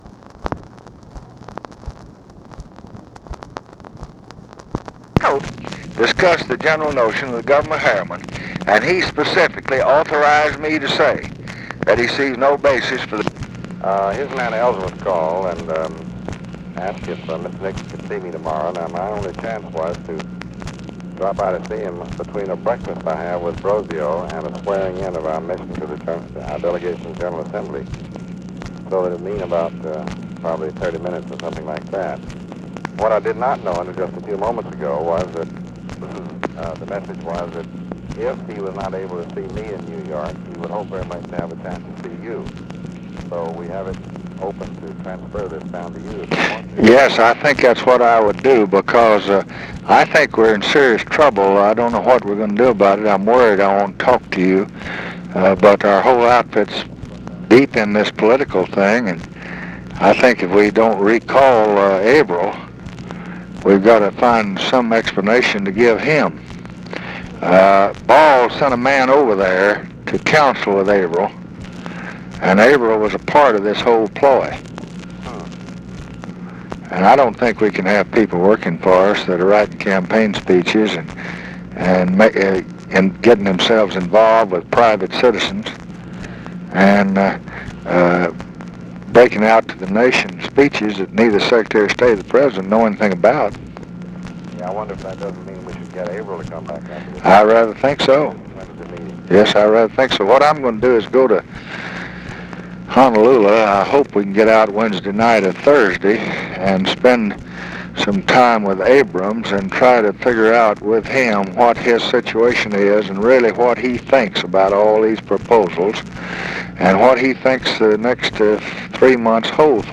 Conversation with DEAN RUSK and OFFICE NOISE, October 7, 1968
Secret White House Tapes